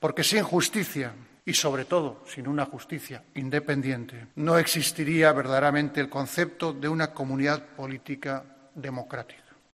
En su discurso en el acto de entrega de despachos a los jueces de la 78ª promoción, que reúne este miércoles a la cúpula judicial en Barcelona, el rey ha recalcado que el respeto a las resoluciones judiciales es una de las "condiciones indispensables" de la democracia, junto al principio de igualdad de todos ante la ley.